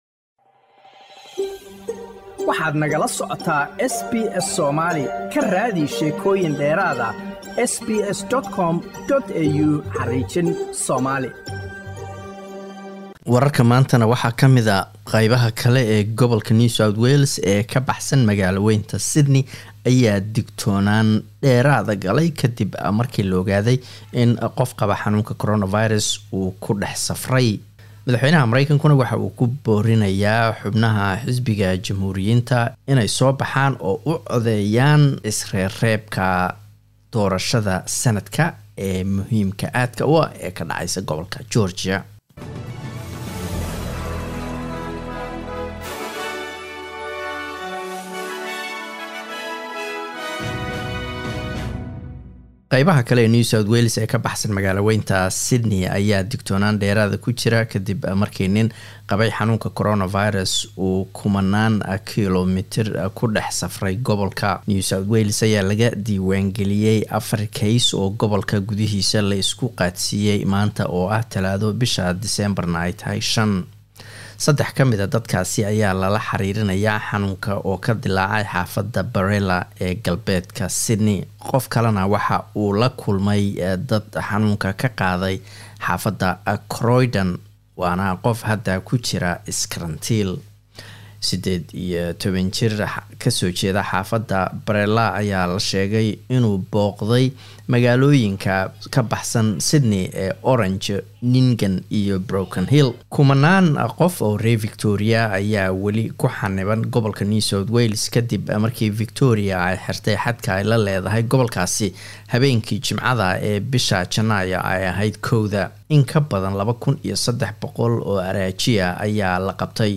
Wararka SBS Somali Talaado 05 Janaayo